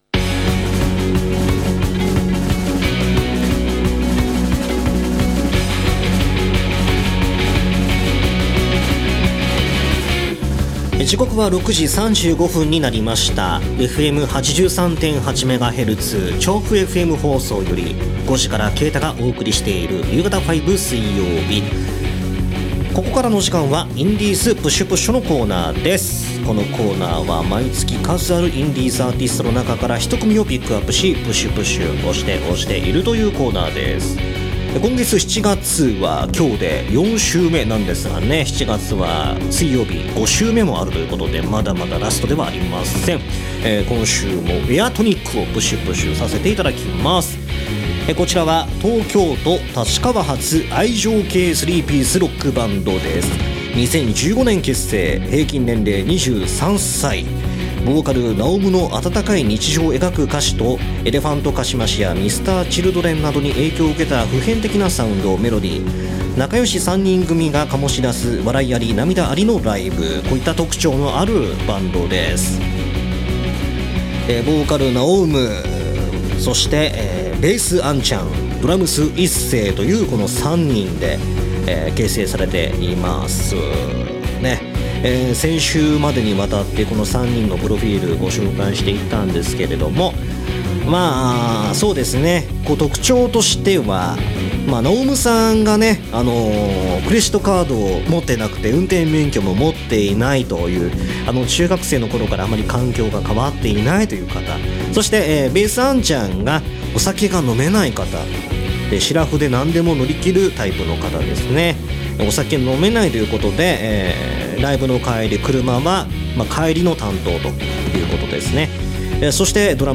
今週も2曲お送りいたしましたので同録きいてね！！！